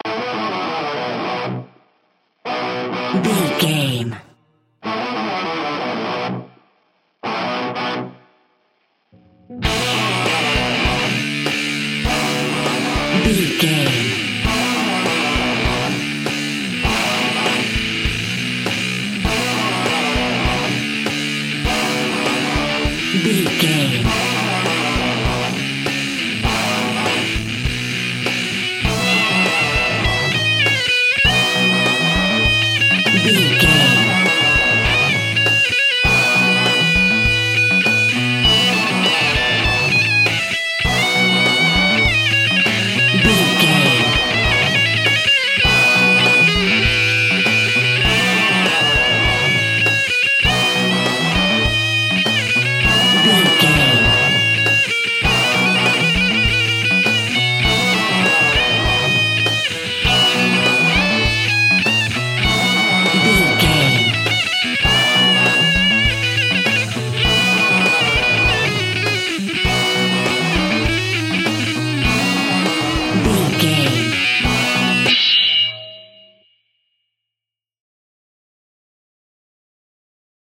Epic / Action
Aeolian/Minor
pop rock
energetic
uplifting
driving
Instrumental rock
distortion
drums
bass guitar
electric guitar